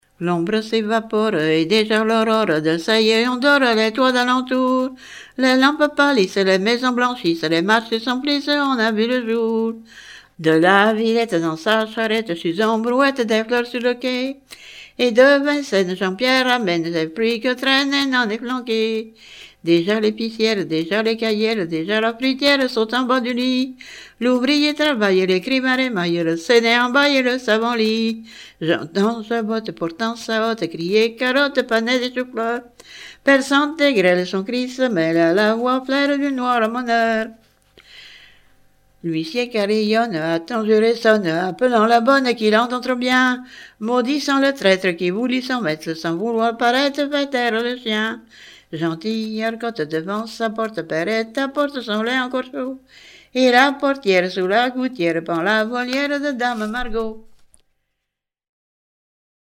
Plaine vendéenne
Genre strophique
Pièce musicale éditée